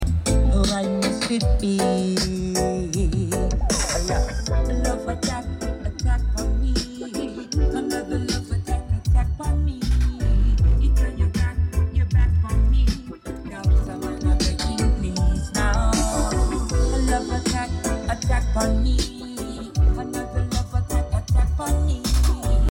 6.5in mids sound great🤙 sound effects free download